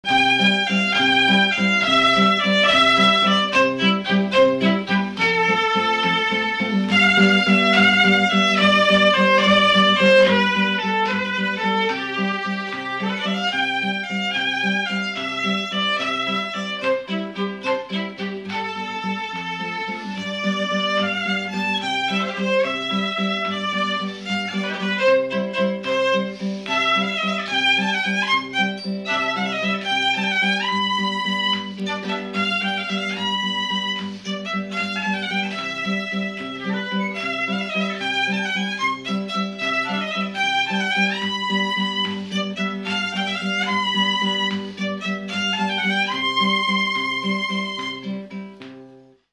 Instrumental
danse : valse
Pièce musicale inédite